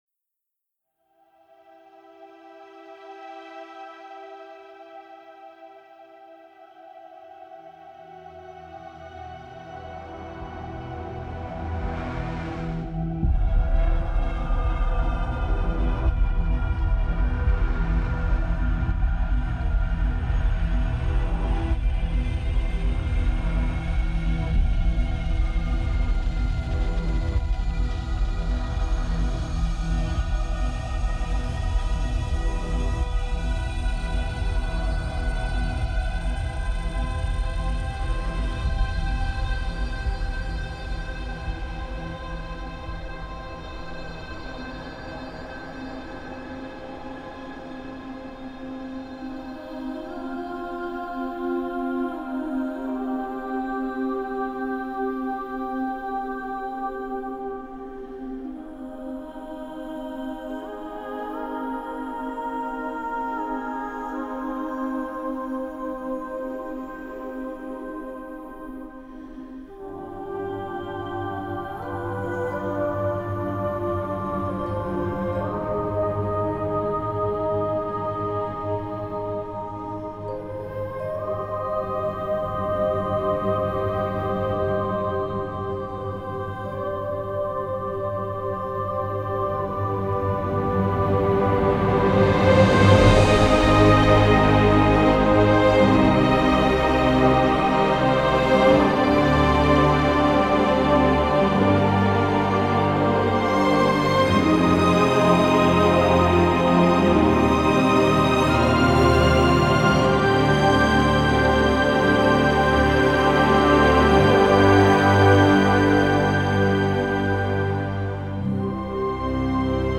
poignant et mélodramatique en diable.